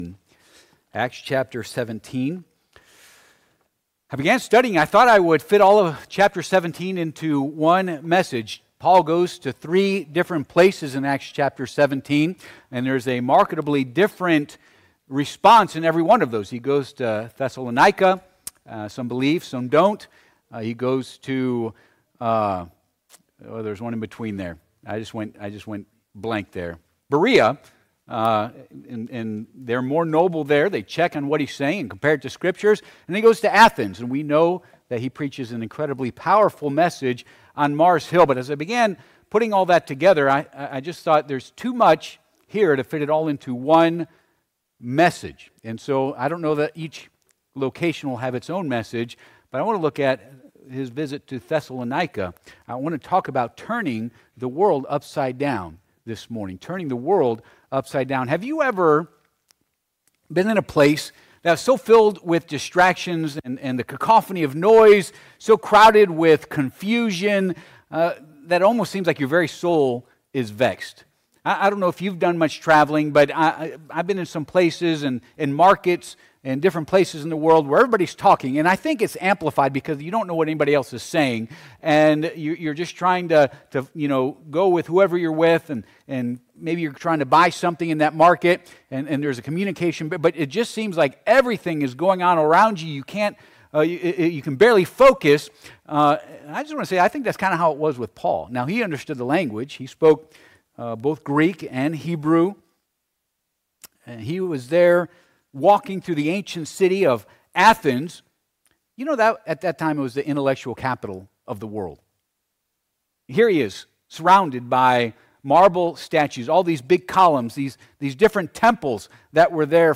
Acts Passage: Acts 17:1-6 Service Type: Sunday AM « What Will Be My Response to the Blessings of the Lord?